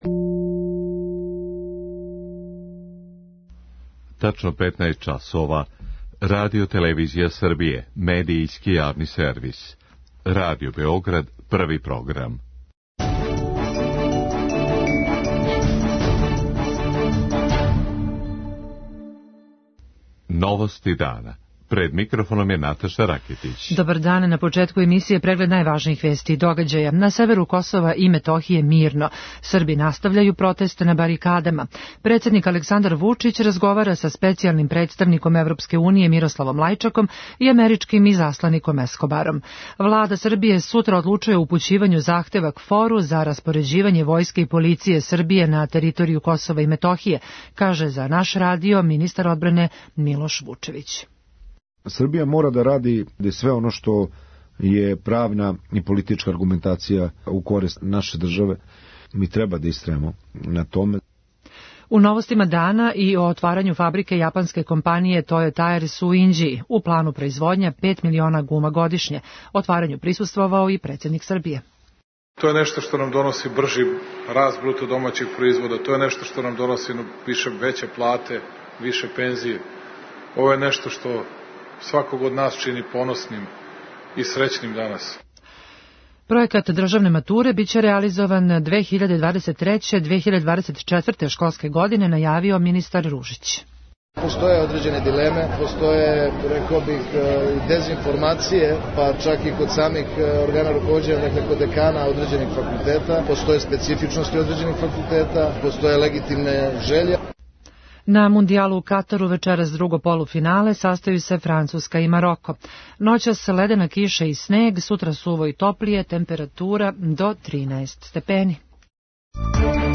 Министар одбране Милош Вучевић у интервјуу за Радио Београд 1 каже да је премијерка позвала и председника државе да учествује у дискусији.